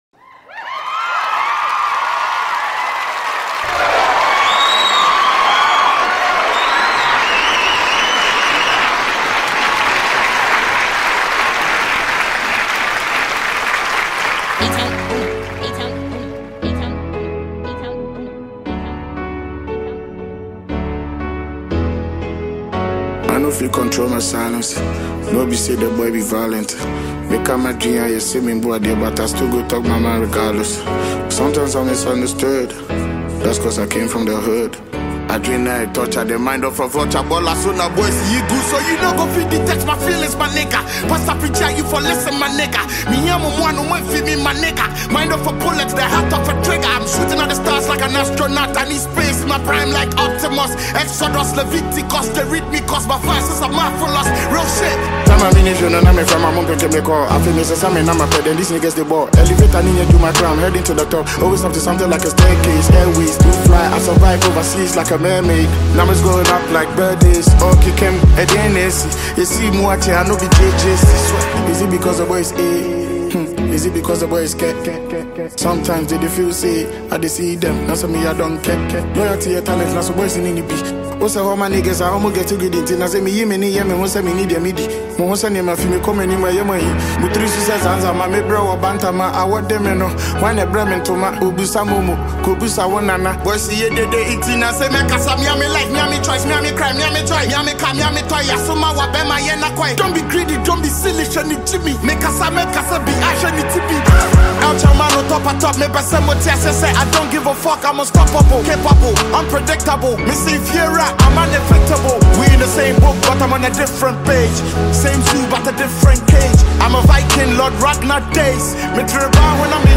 a renowned Ghanaian hip-hop artist